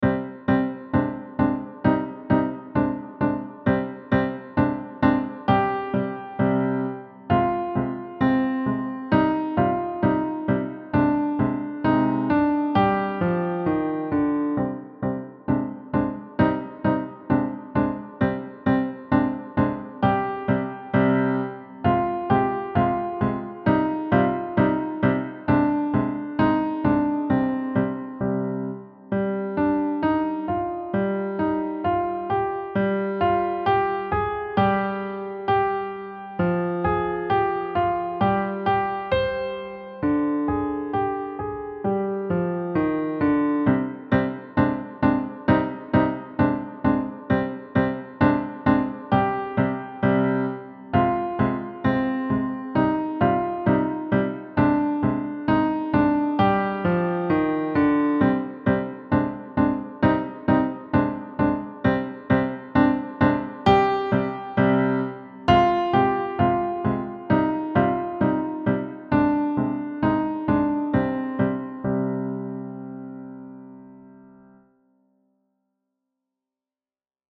Key: C Minor penta
Time signature: 4/4
• Steady left-hand march pattern
• Crisp articulation and rhythmic clarity
• Hands-together coordination with playful accents